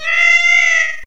(184.52 KB, se_cat01.wav)